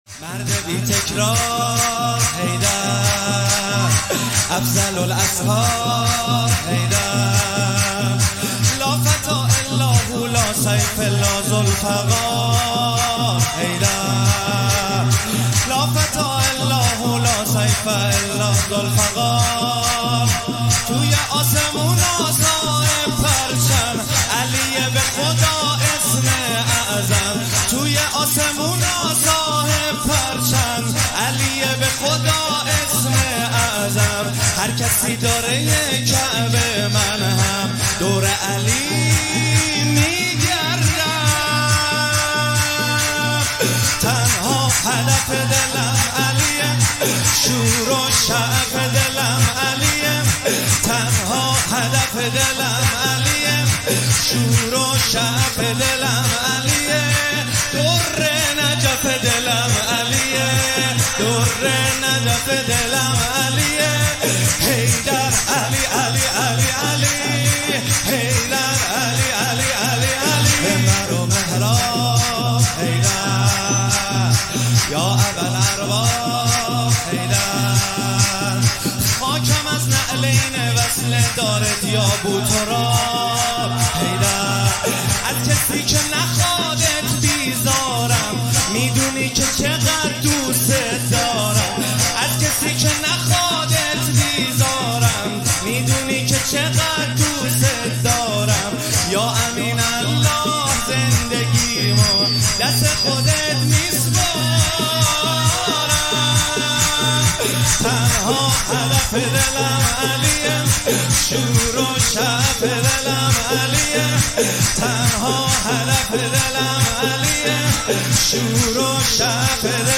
ولادت امام رضا (ع) هیئت ام ابیها قم